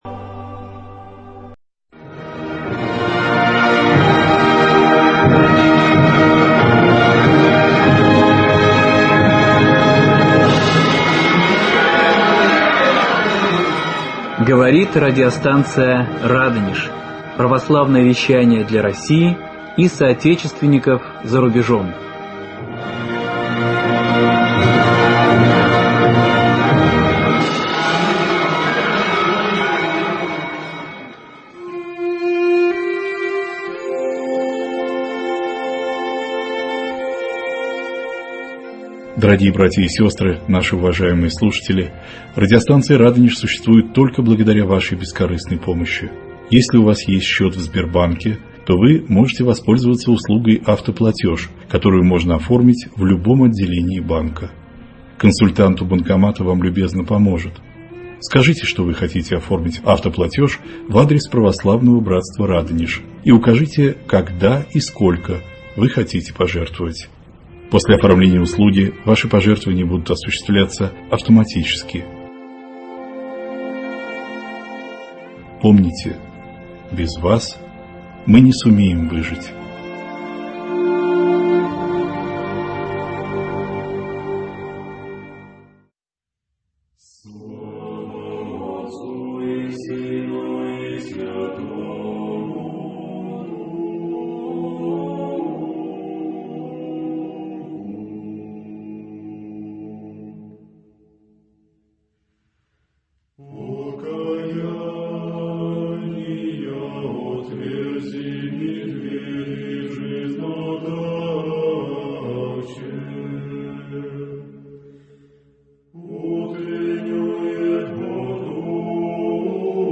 Беседа 11 и 12